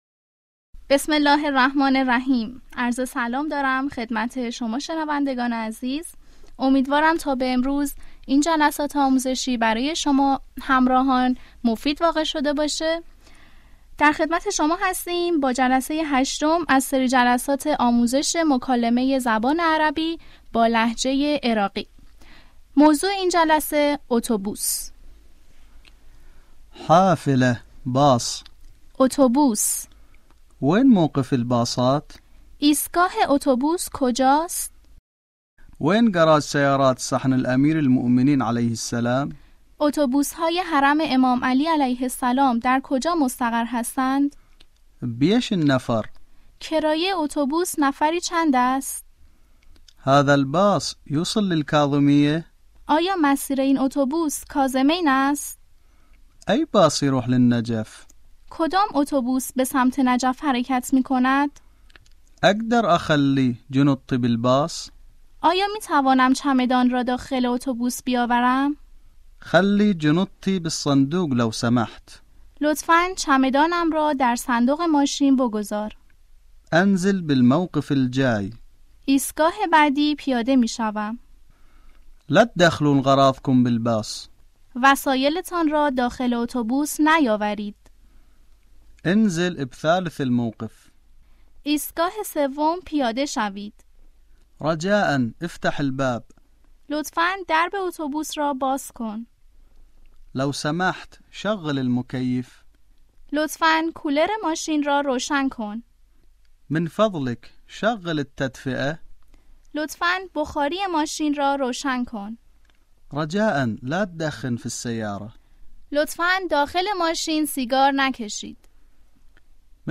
آموزش مکالمه عربی به لهجه عراقی - جلسه هشتم - اتوبوس